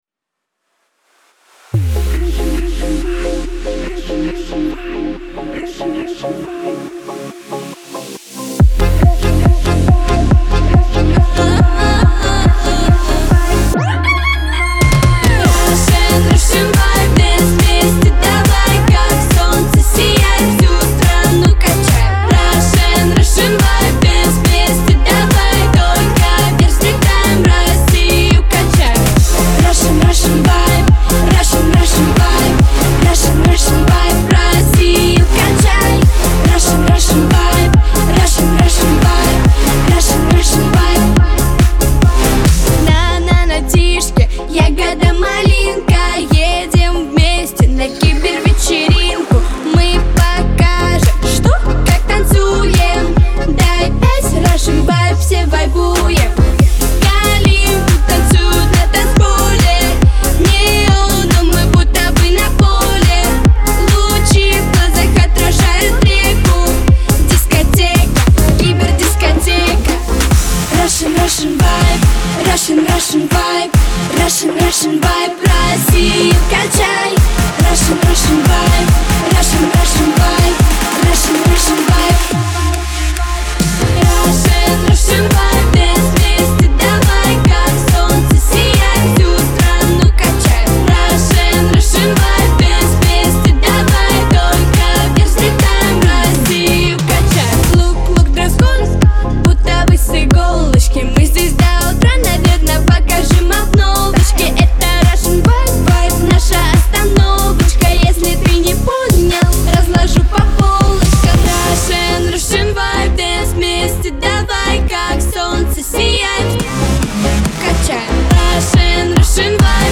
pop , эстрада